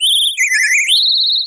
Cri de Minisange dans Pokémon HOME.